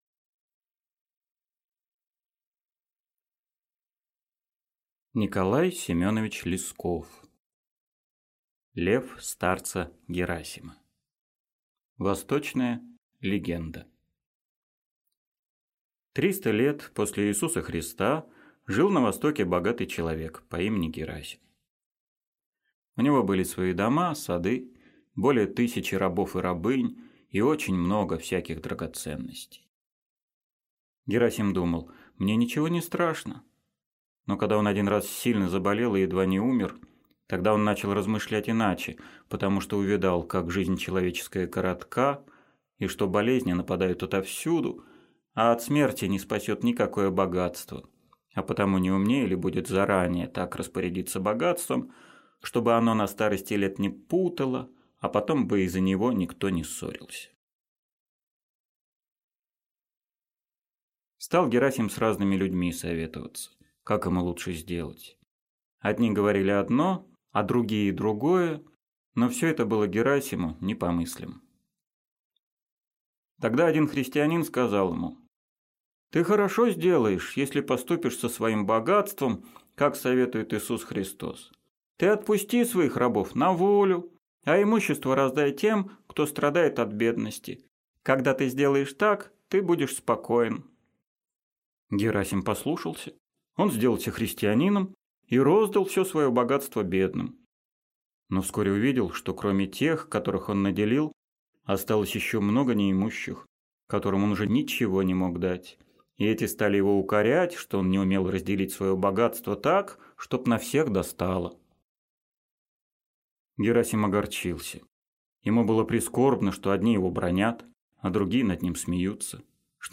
Аудиокнига Лев старца Герасима | Библиотека аудиокниг
Aудиокнига Лев старца Герасима Автор Николай Лесков Читает аудиокнигу Евгений Лебедев.